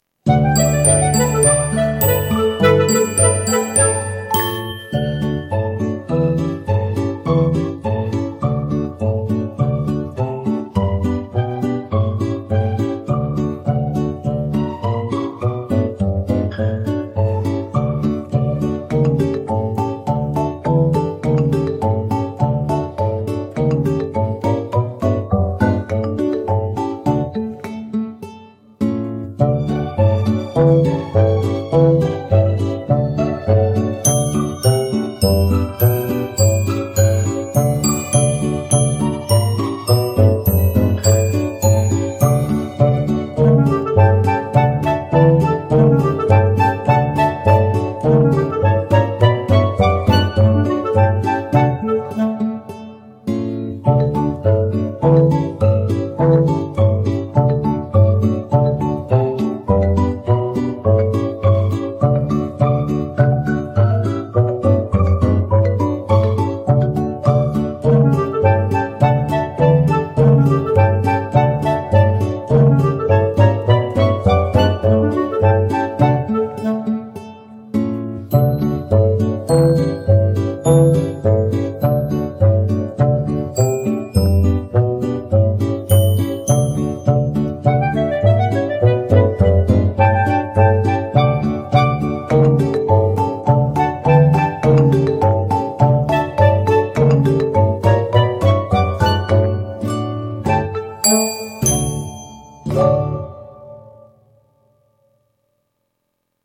Песня. Куда пропал дождик